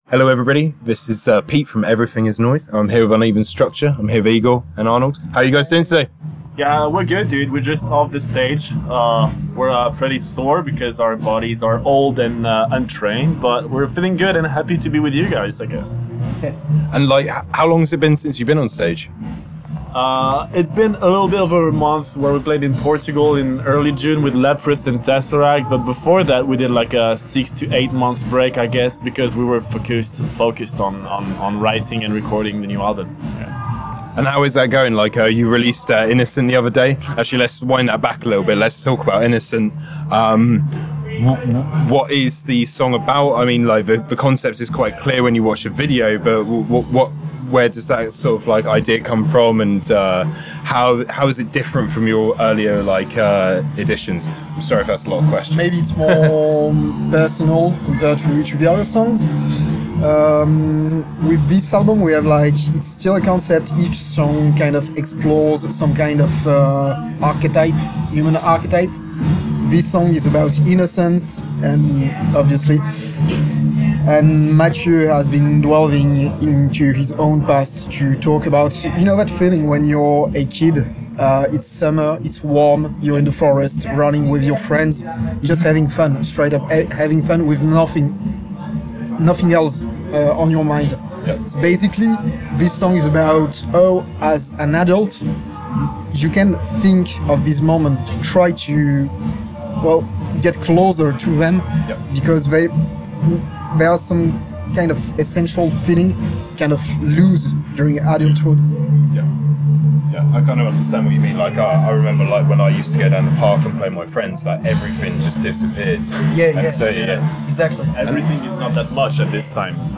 Thankfully we got to catch up once again at Radar Festival, rehashing some of our conversation from back then, and of course broaching all of the exciting new topics in Uneven Structure‘s world.
We caught up with them after they finished their set at the festival, having a great discussion about how they bring such a crisp sound to their live performances, their forthcoming tours, and of course a dive into the concept and ideas behind Paragon.